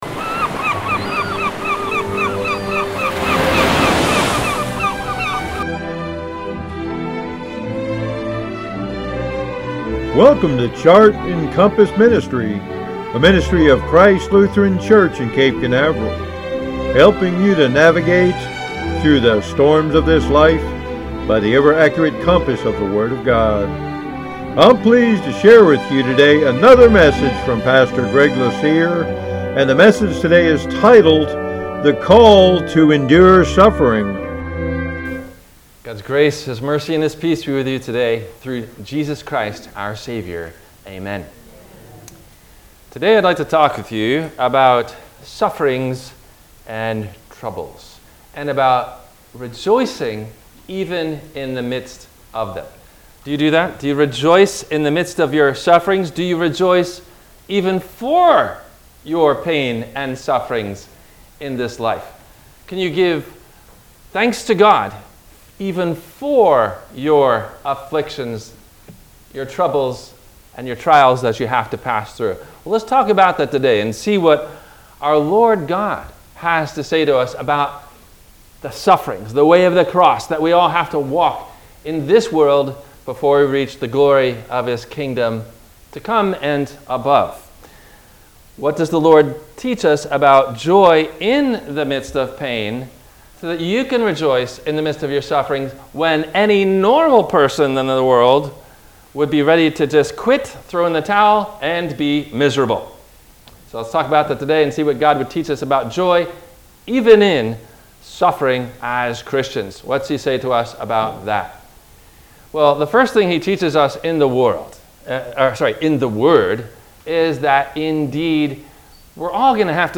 No Questions asked before the Sermon message:
Including Intro and Plug
WMIE Radio – Christ Lutheran Church, Cape Canaveral on Mondays from 12:30 – 1:00